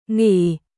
nghỉ休む／休憩するンギー